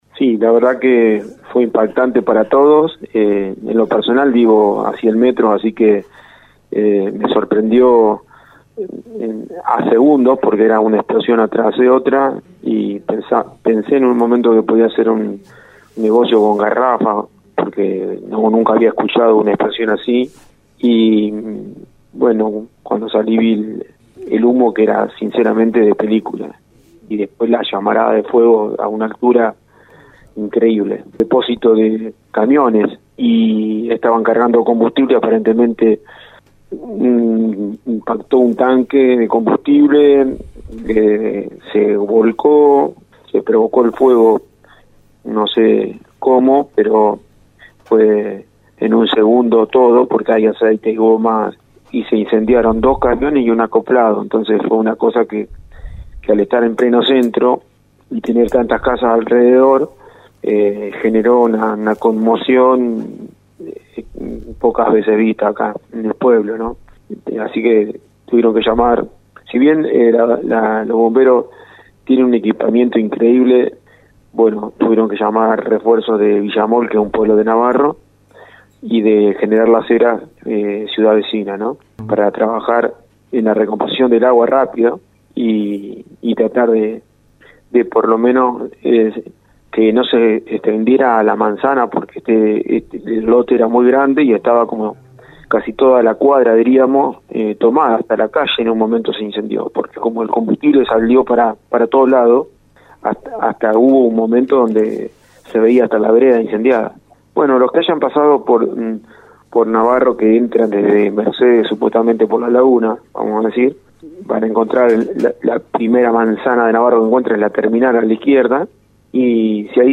EN RADIO UNIVERSO